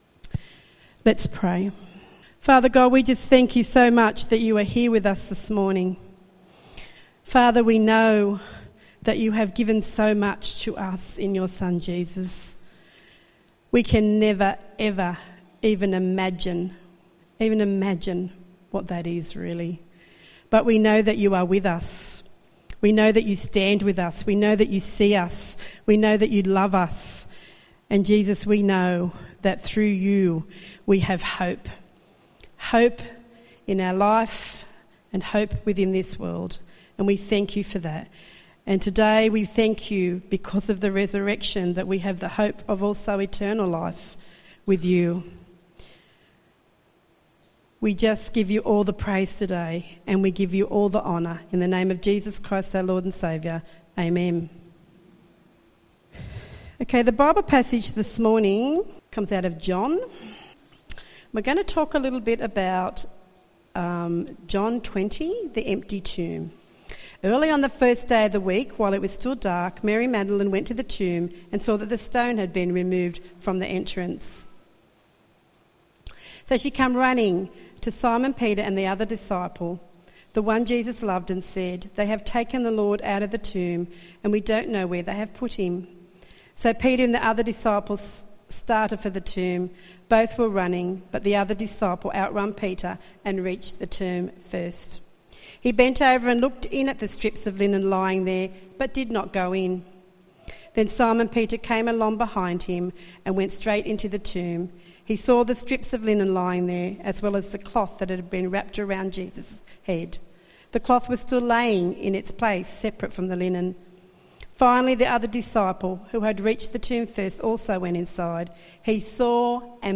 Bible reading and message from the 10AM meeting at Newcastle Worship & Community Centre of The Salvation Army, on the occasion of Easter 2022. The bible reading was taken from John 20.